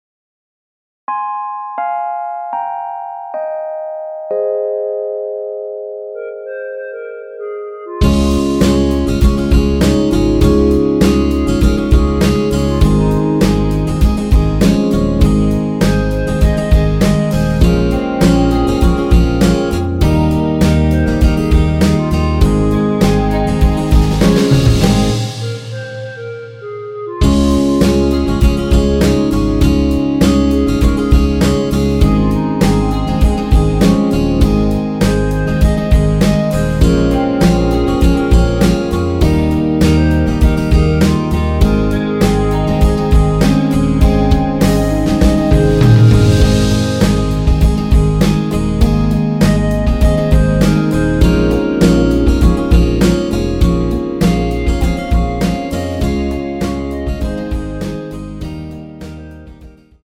원키 멜로디 포함된 MR입니다.
Ab
앞부분30초, 뒷부분30초씩 편집해서 올려 드리고 있습니다.
중간에 음이 끈어지고 다시 나오는 이유는